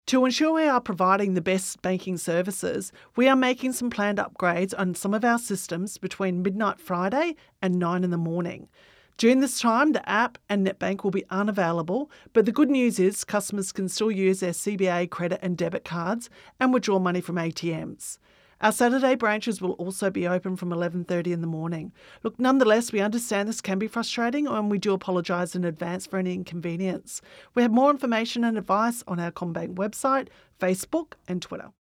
AppUpgradeStatement1Female.mp3